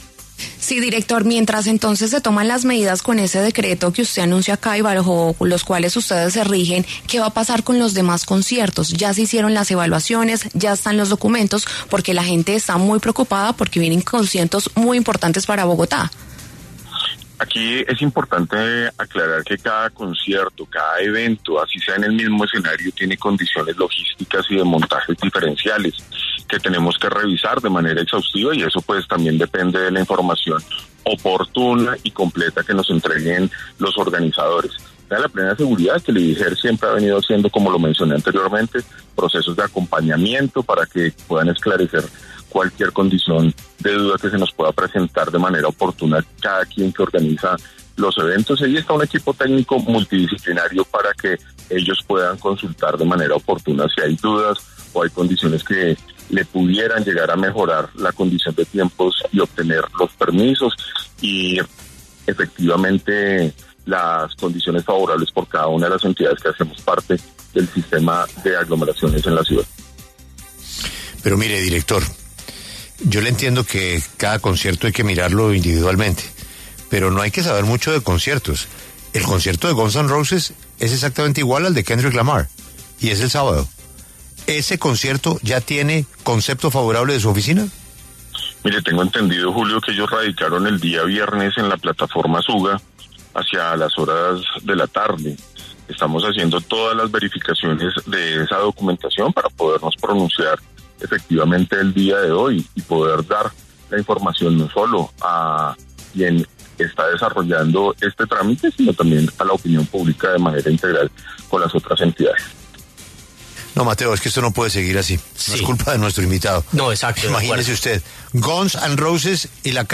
Guillermo Escobar, director del IDIGER, habló en La W sobre la realización de próximos conciertos en Vive Claro en Bogotá.